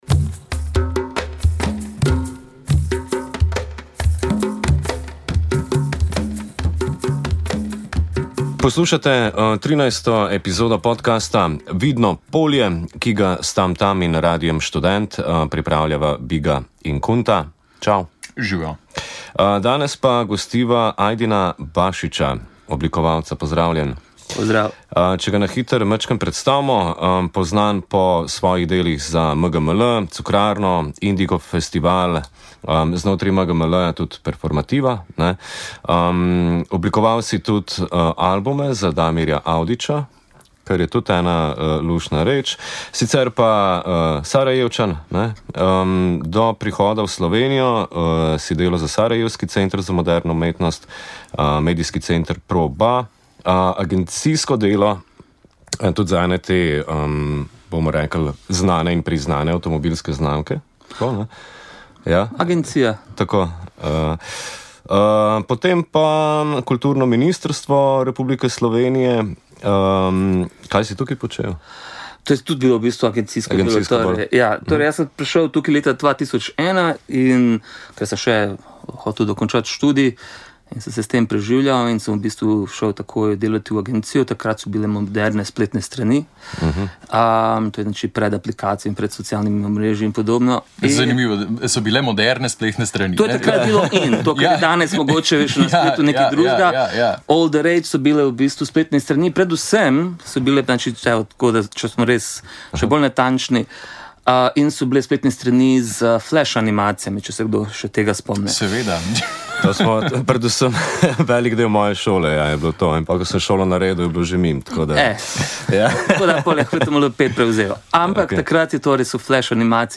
Ustvarjeno v studiu Radia Študent.